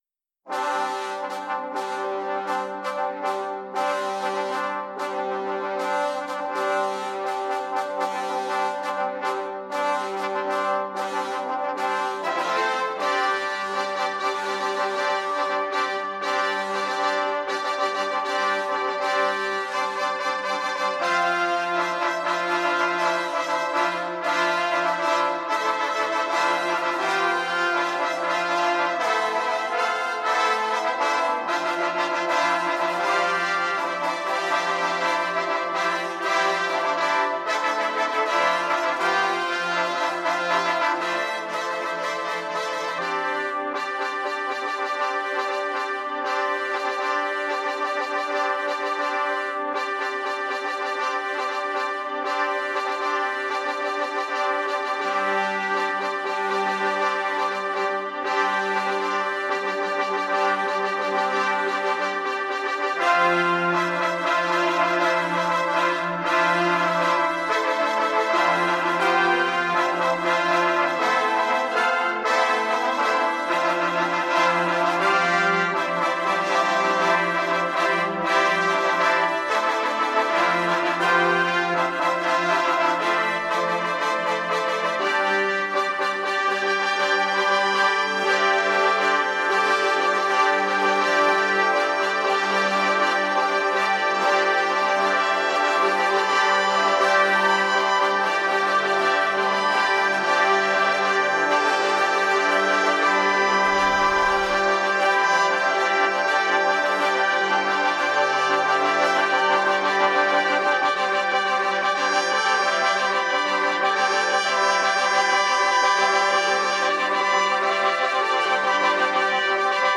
Un doigt de death metal, une pincée de metal indus, de l’underground, des news et du black metal.